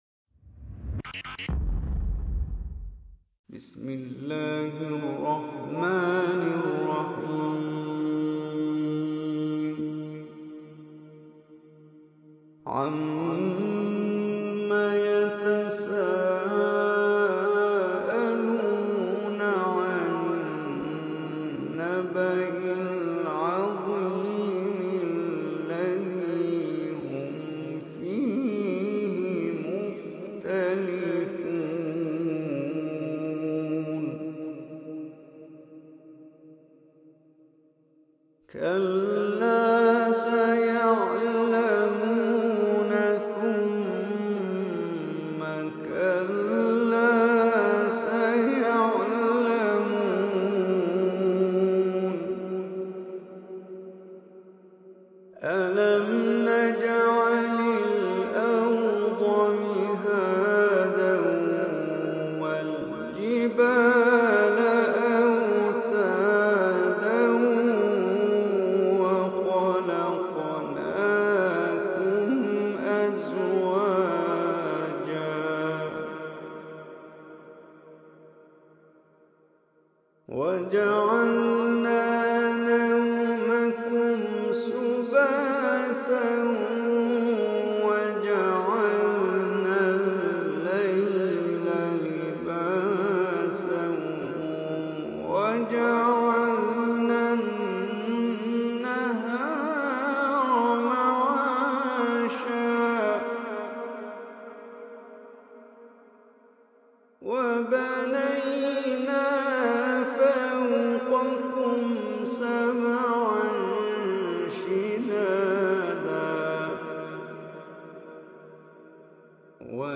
Surah Naba Recitation by Omar Hisham Al Arabi
Surah Naba is 78 surah of Quran Shareef. Listen or play online mp3 tilawat / recitation in the beautiful voice of Omar Hisham Al Arabi.